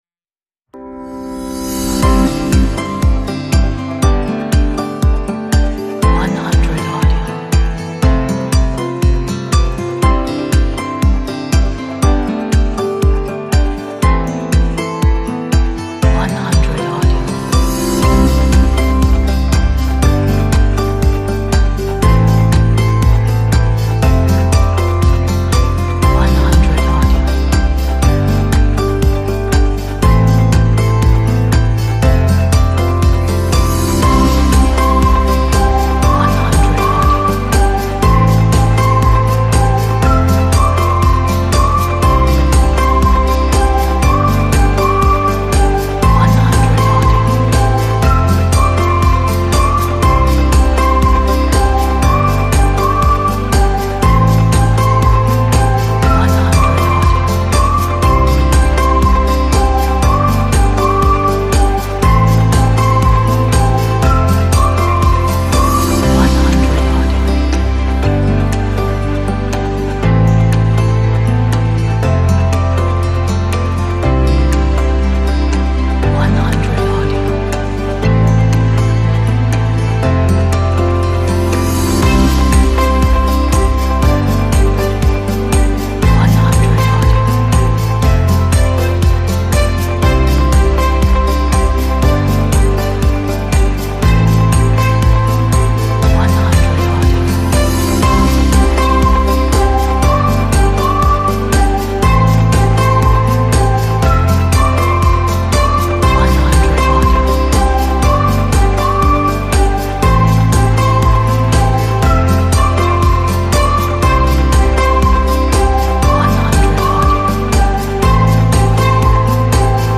This track will give you a great mood.
Beautiful melody will inspire you!